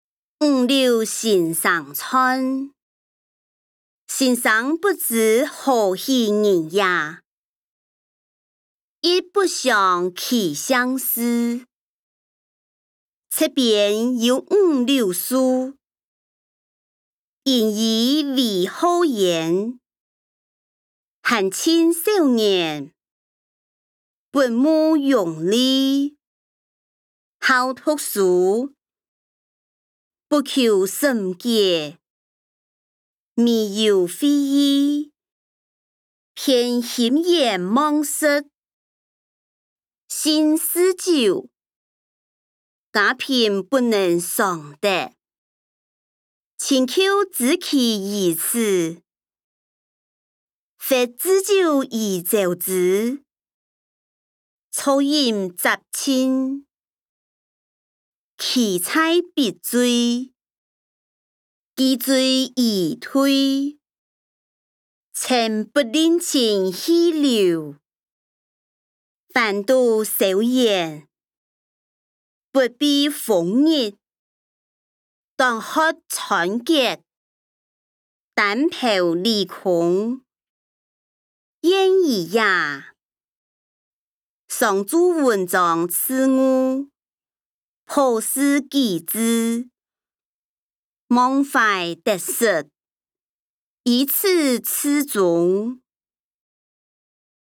歷代散文-五柳先生傳音檔(四縣腔)